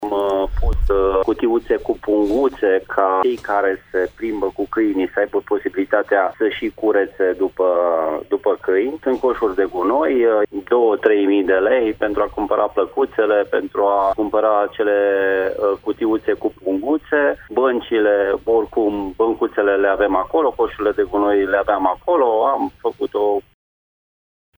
Investitia nu a fost mai mare de 3000 de lei, spune primarul din Fagaras, Gheorghe Sucaciu: